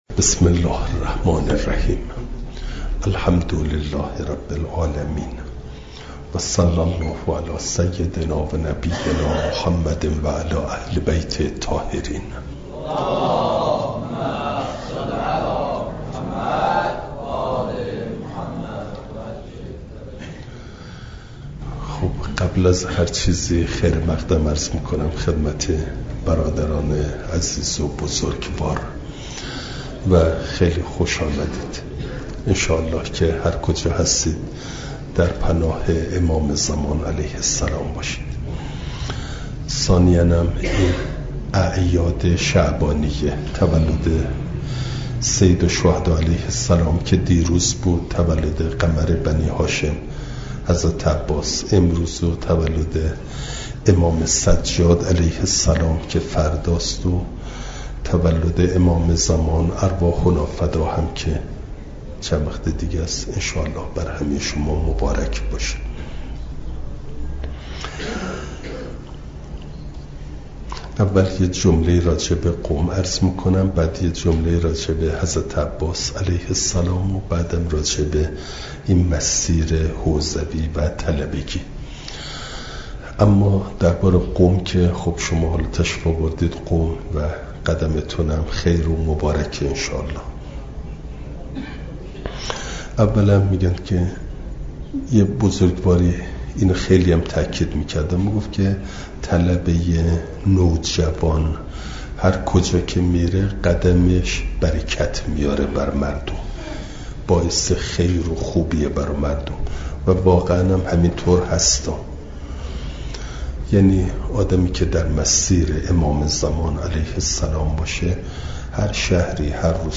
بیانات اخلاقی
شنبه ۴ بهمن ماه ۱۴۰۴، دارالقرآن علامه طباطبایی(ره)